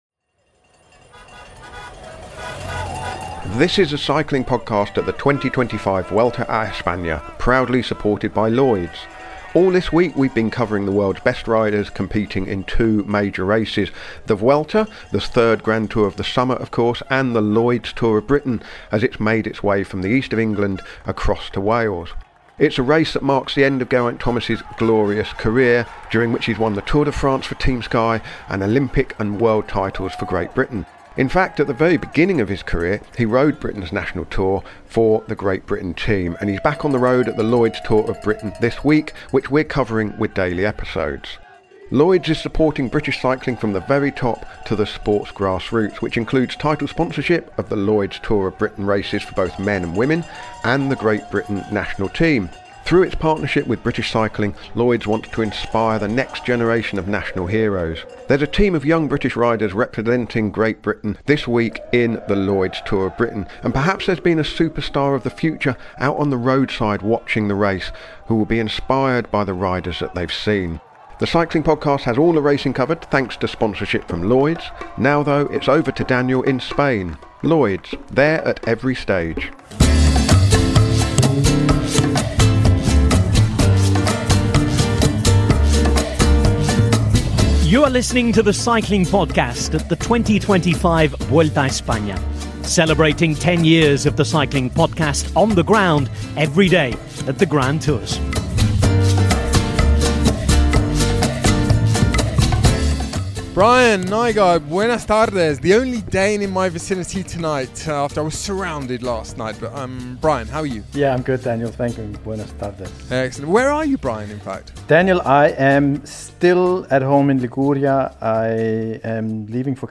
Each episode features in-depth race analysis, exclusive interviews, and daily postcards from Spain, capturing the atmosphere and stories from along the route.